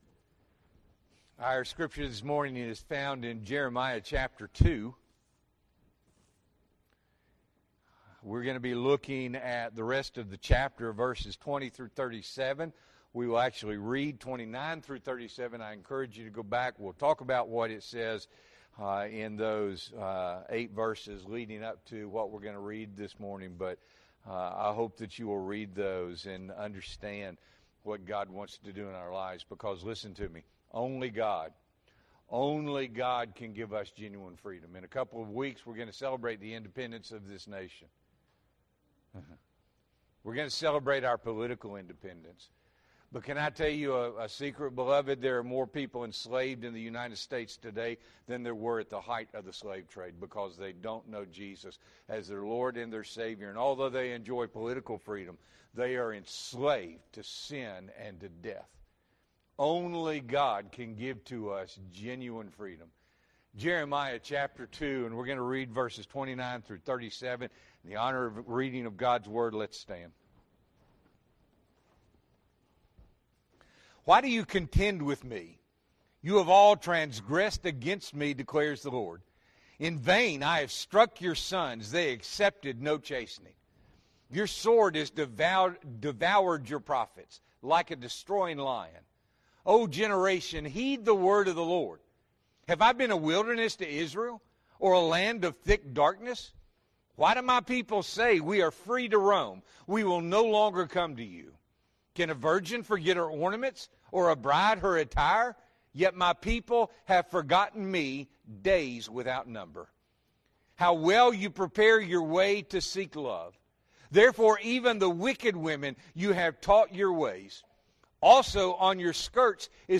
June 23, 2024 – Morning Worship